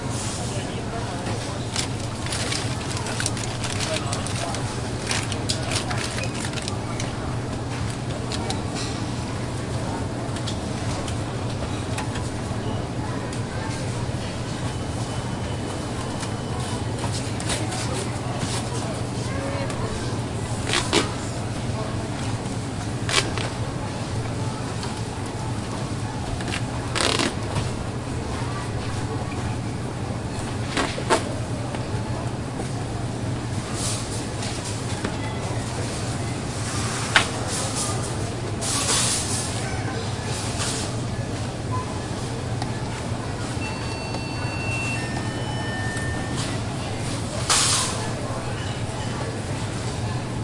描述：超市环境
Tag: 儿童 语音 扬声器 贴标签 超市 球童 人业 贸易